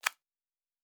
Sci-Fi Sounds / Interface / Click 11.wav
Click 11.wav